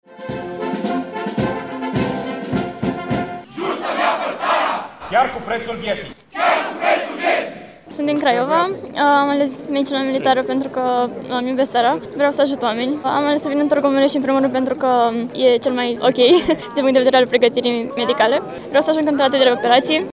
Festivitatea a avut loc în incinta Cetăţii Medievale din Tîrgu Mureş.